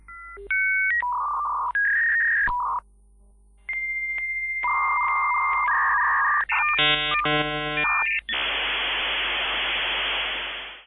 loading-icon.mp3